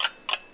Mode Campaign Ticktock Sound Effect
mode-campaign-ticktock.mp3